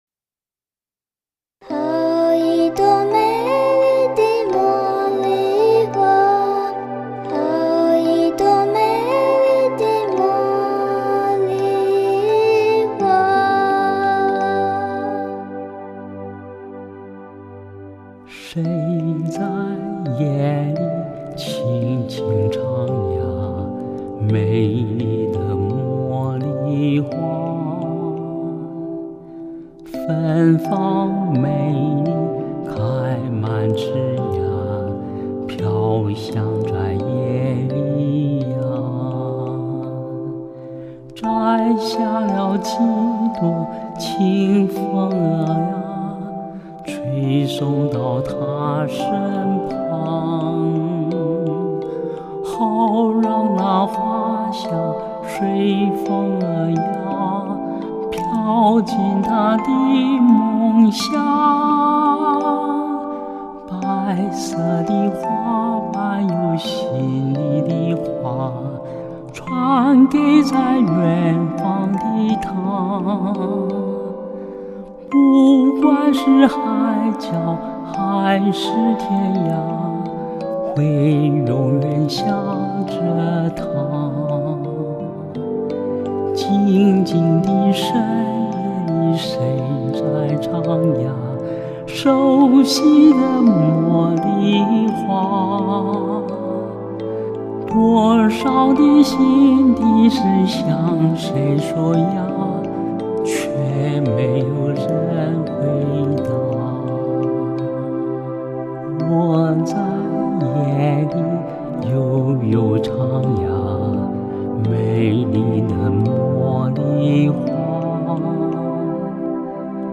透过童稚的天真、温婉的女声、如醇酒精酿的天籁男声，
稚润的童音如春风拂面惹人怜爱、婉约的女声如秋空浮云引人遐思，
温柔的男声如夜湖划舟令人陶醉，由德律风根(TELEFUNKEN)的真空管呈现出十二首天籁乐章，